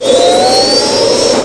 jet.mp3